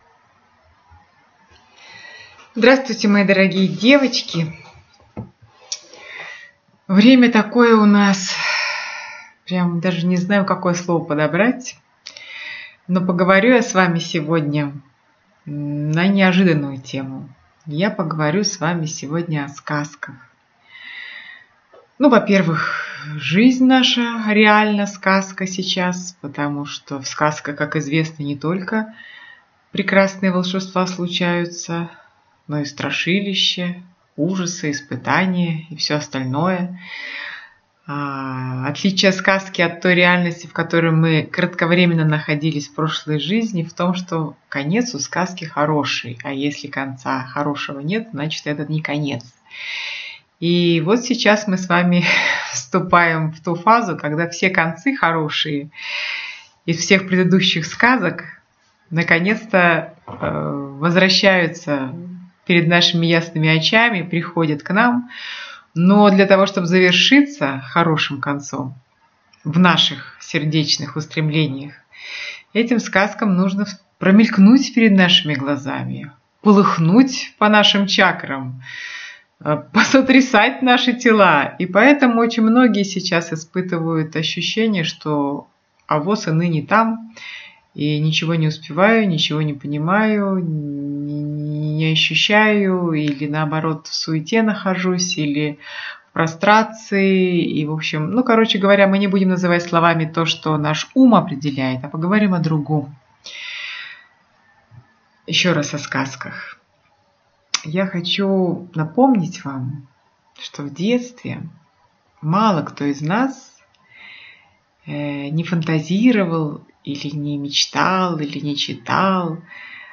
А в качестве новогоднего подарка я делюсь с Вами записью клубного вебинара «Заповедный лес» — о творящих внутренних пространствах нашего сердца.